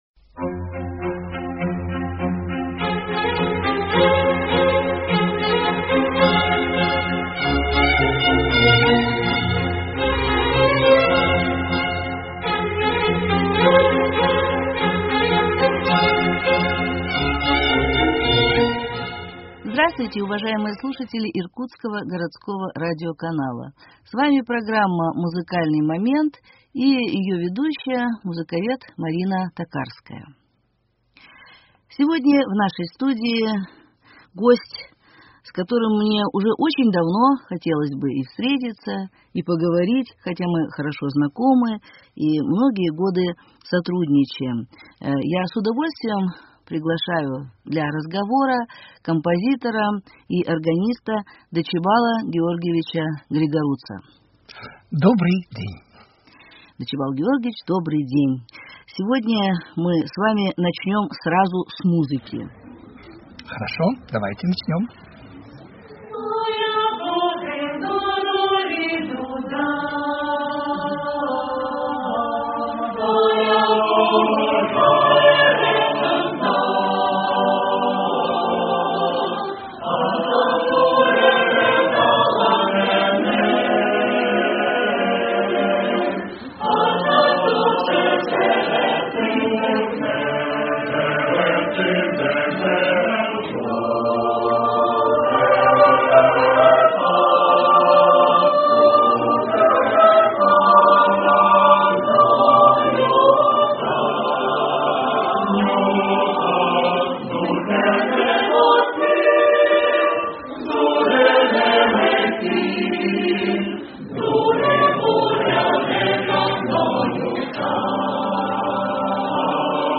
беседует с композитором, органистом